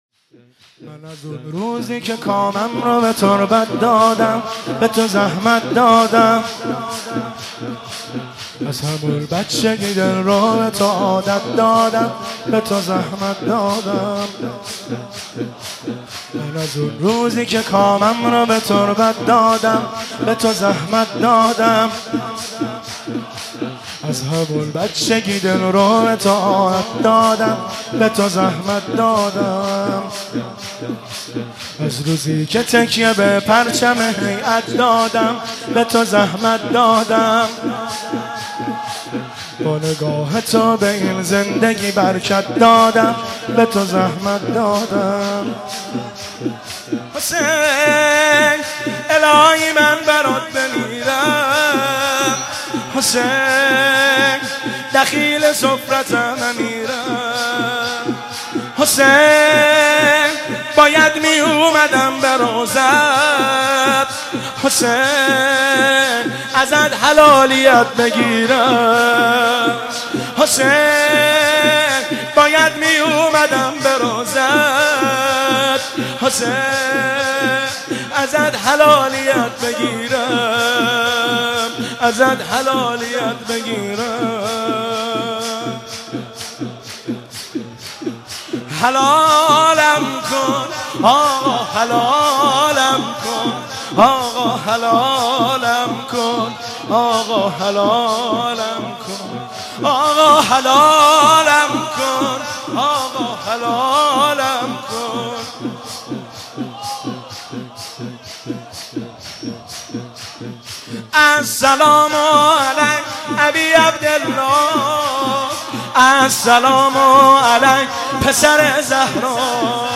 مداحی من از اون روزی که کامم رو به تربت دادم(شور) فاطمیه 1394 هیئت بین الحرمین